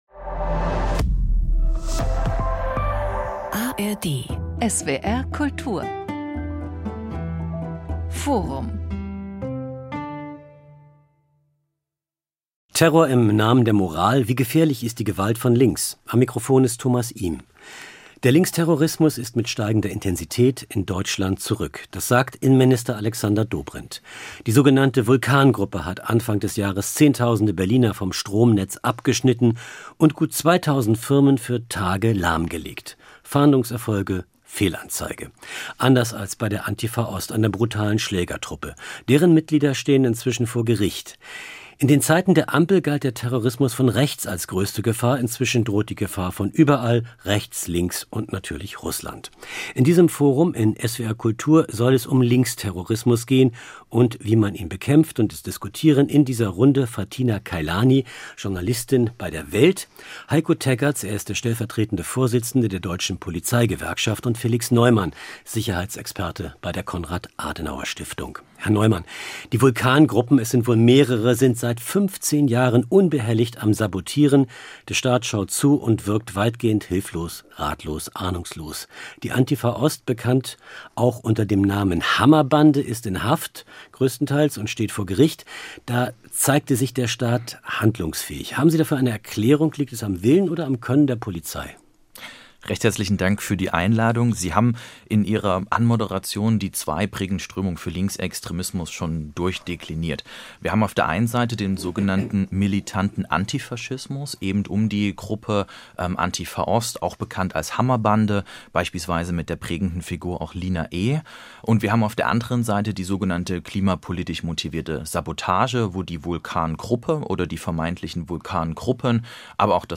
In diesem Forum in SWR-Kultur soll es um Linksterrorismus gehen, und wie man ihn bekämpft.